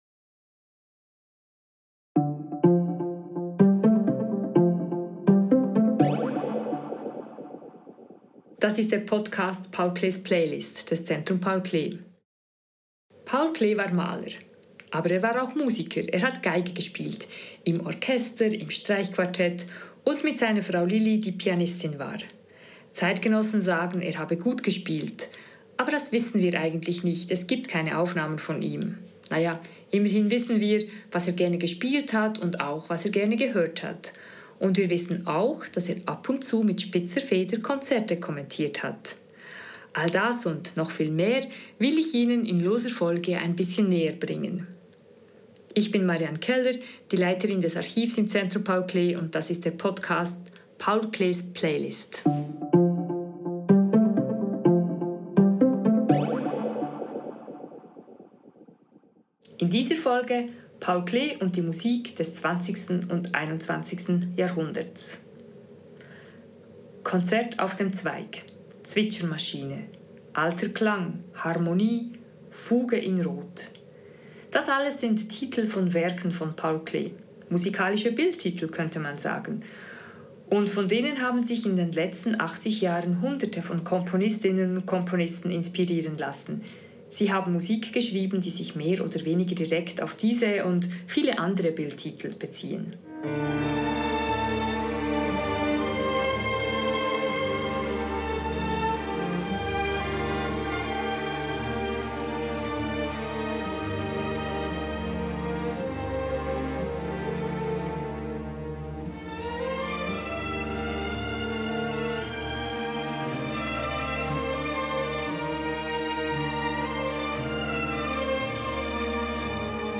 Warum das so ist und wie diese zeitgenössische Musik klingt, erfahren Sie in diese Folge von «Paul Klees Playlist». Gespielte Werke (Ausschnitte):